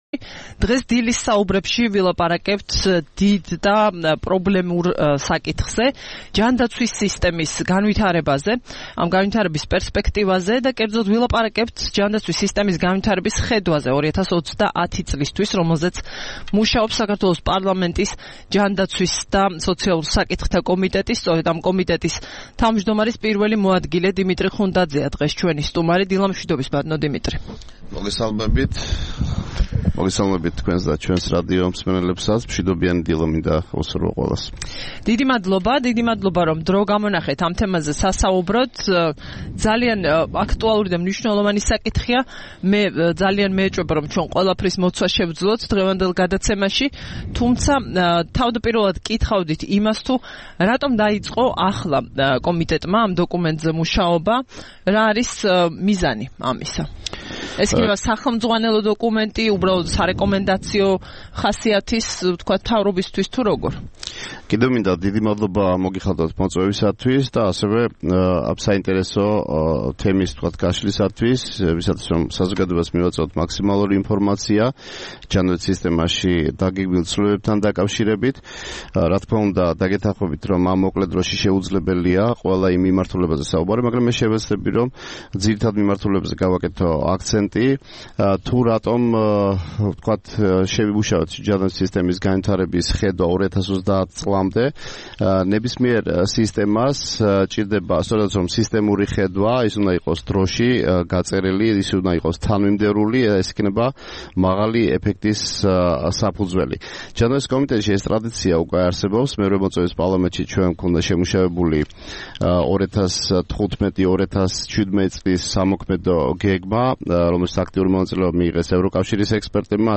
4 აგვისტოს რადიო თავისუფლების "დილის საუბრების" სტუმარი იყო დიმიტრი ხუნდაძე, ჯანდაცვისა და სოციალურ საკითხთა საპარლამენტო კომიტეტის თავმჯდომარის პირველი მოადგილე.